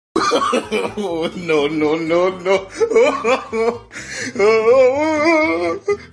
oh no no no no laugh
Tags: memes